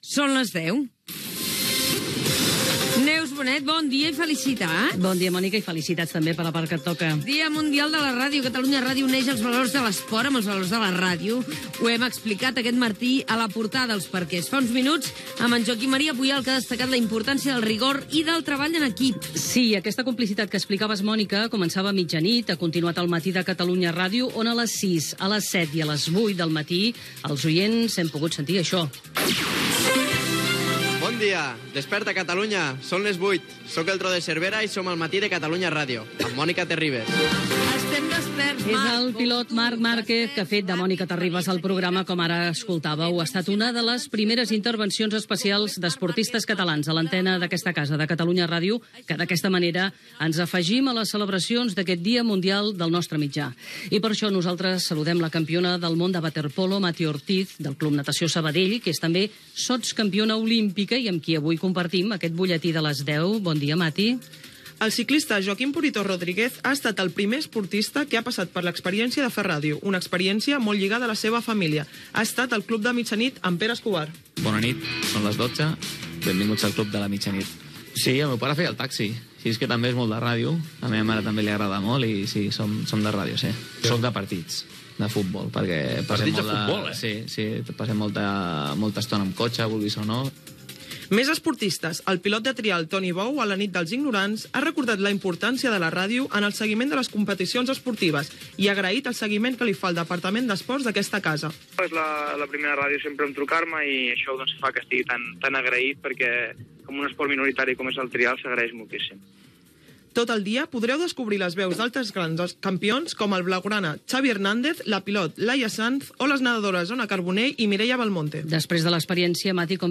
Mònica Terribas dona pas al butlletí informatiu. Coincidint amb el Dia Mundial de la Ràdio, diferents esportistes participen als programes de l'emissora. La jugadora de waterpolo, Mati Ortiz, copresenta el butlletí de les 10 del matí informant de la participació d'altres esportistes, com Joaquim Rodríguez "Purito" o Toni Bou, a la programació.
Informatiu